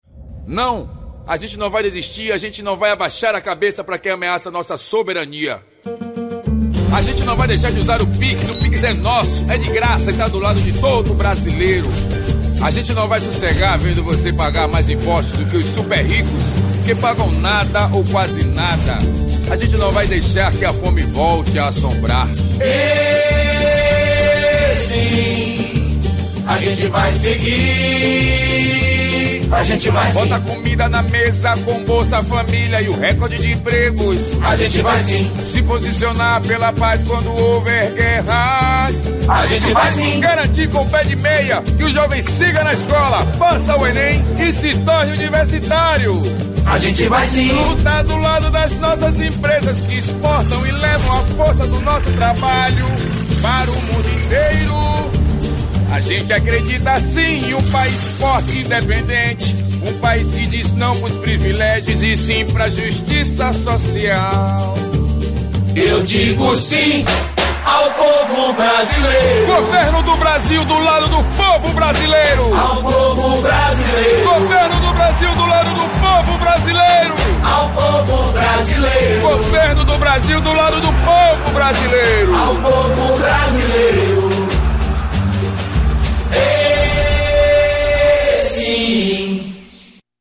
Spots